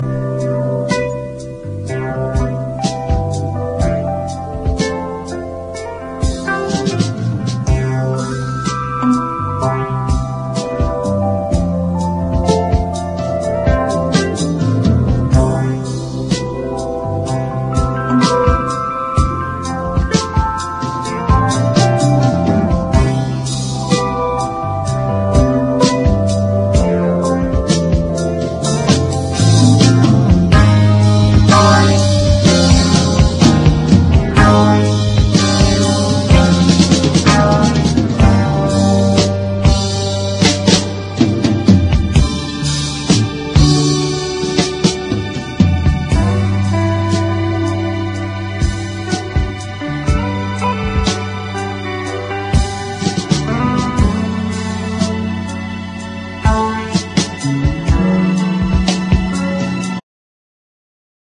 ROCK / 70'S / PUB ROCK
パブで鍛えられた渋い喉と、ロックにリズム＆ブルース、レゲエと何でもこなす地味溢れるサウンドが魅力のパブ・ロックな名作。